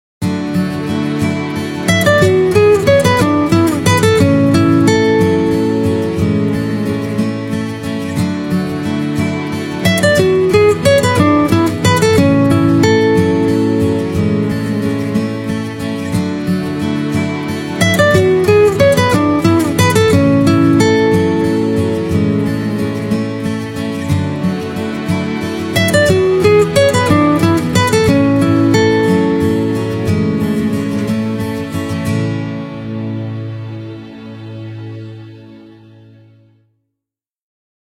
гитара
мелодичные
без слов
инструментальные
приятные
Рингтон, сыгранный на гитаре.